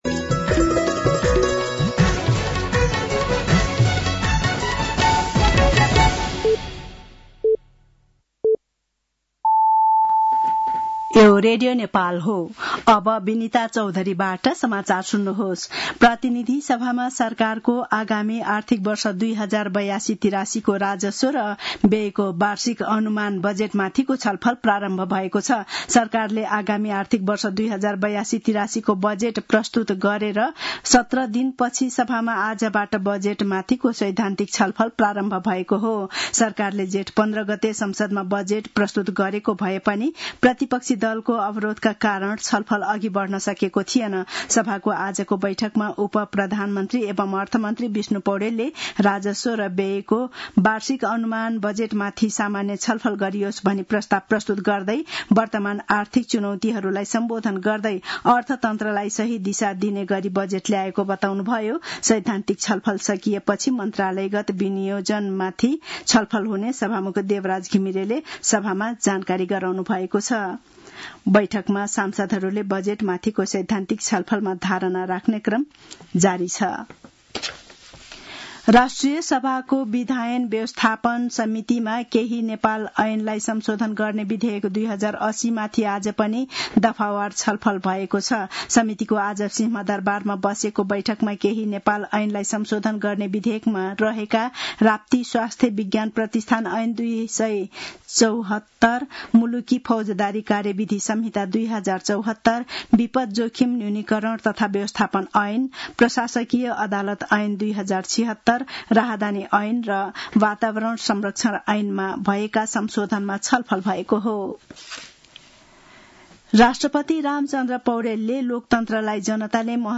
साँझ ५ बजेको नेपाली समाचार : १ असार , २०८२
5.-pm-nepali-news-1-3.mp3